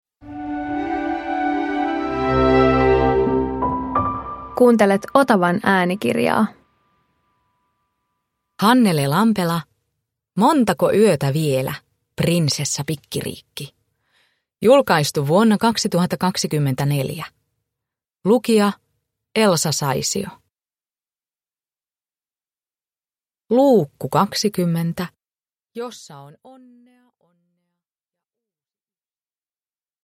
Montako yötä vielä, Prinsessa Pikkiriikki 20 – Ljudbok